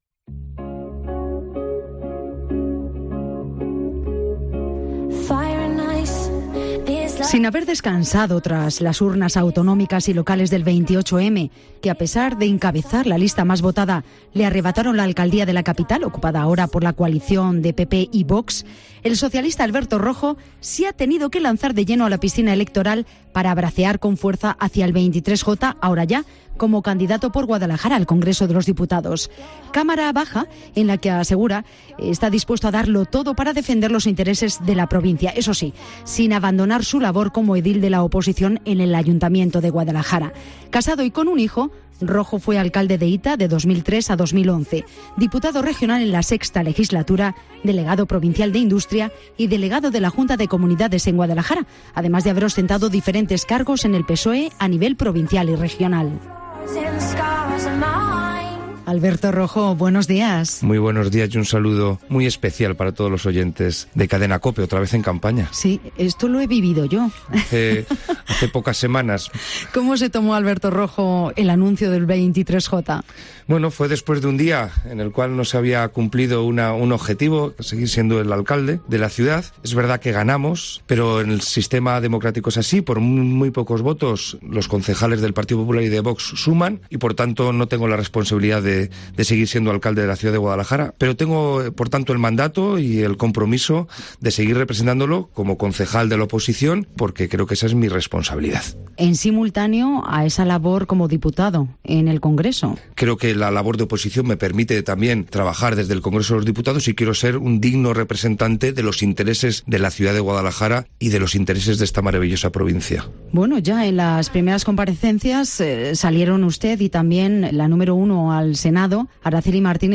El candidato del PSOE al Congreso de los Diputados por Guadalajara en Mediodía COPE Guadalajara